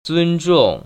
尊重[ zūnzhòng ]